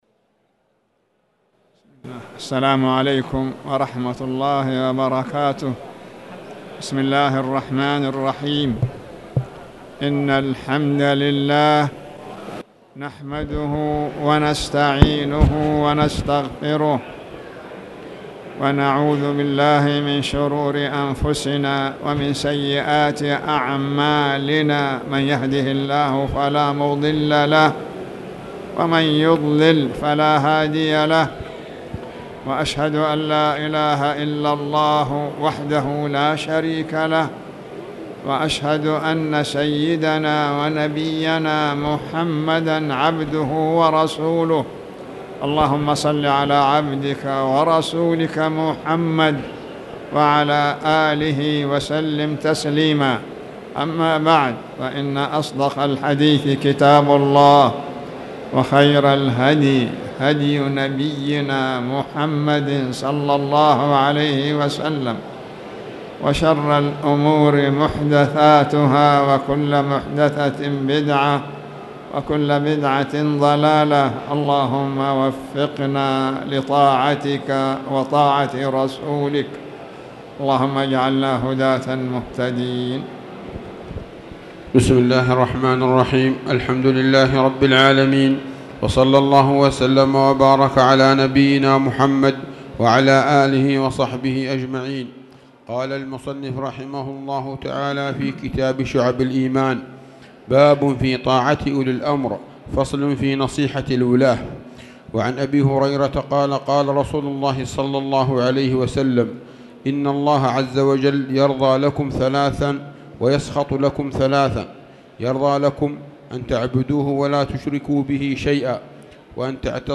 تاريخ النشر ١ محرم ١٤٣٨ هـ المكان: المسجد الحرام الشيخ